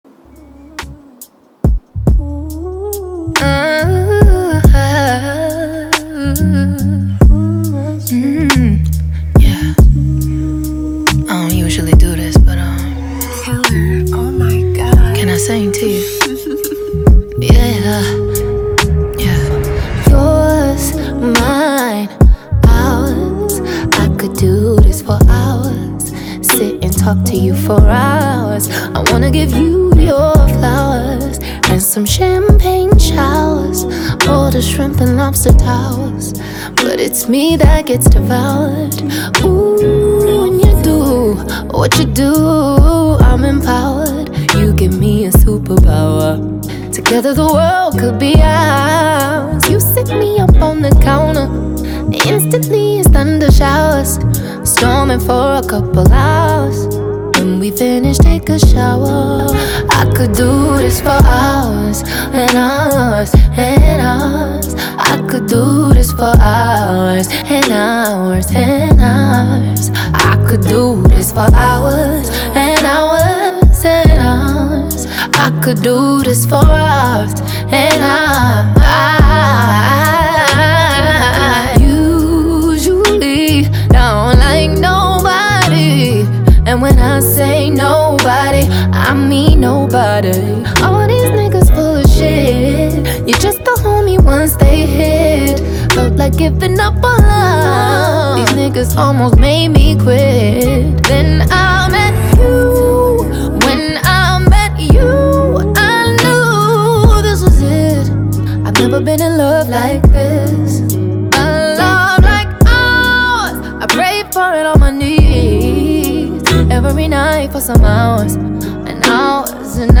American singer
R&B song